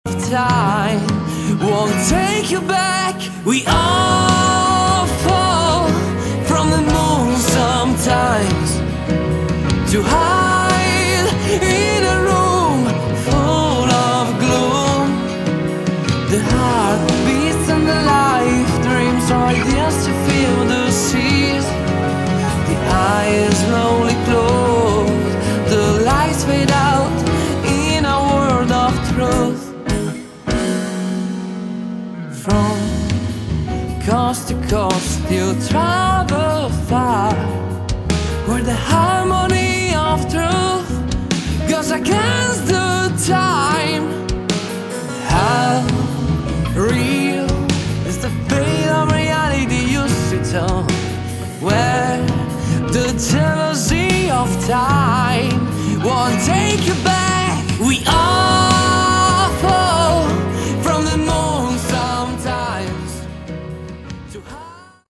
Category: Hard Rock
vocals
drums
guitars
bass
keyboards